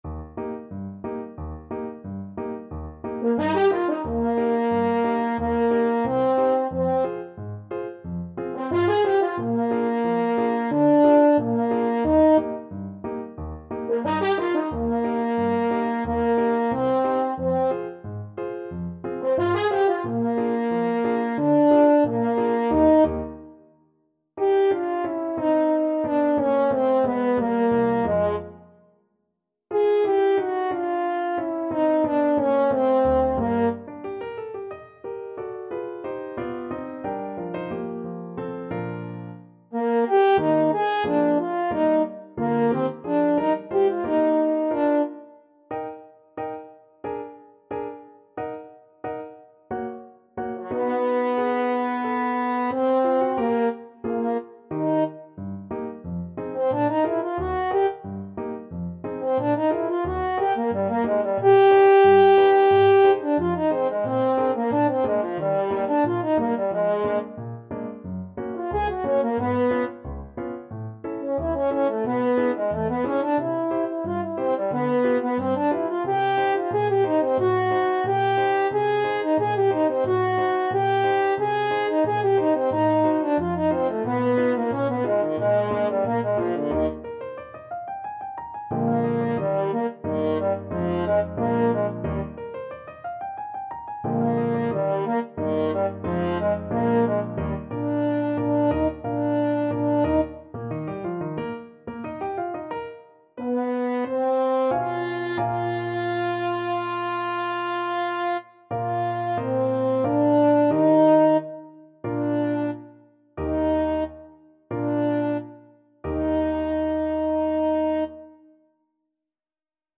Allegro Moderato [ = c.90] (View more music marked Allegro)